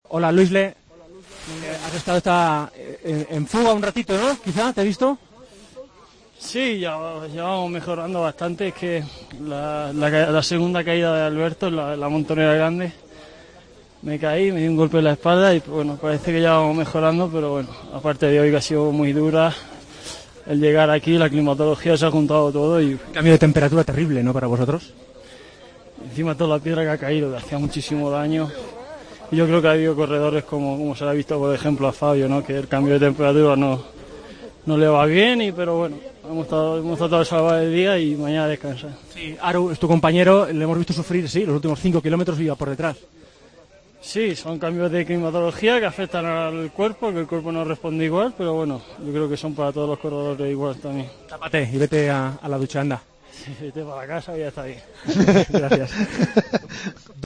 El ciclista español ha atendido a los medios de comunicación al término de la novena etapa y ha mostrado las dificultades que ha pasado su equipo con los cambios meteorológicos: "A Fabio no le van bien los cambios de temperatura.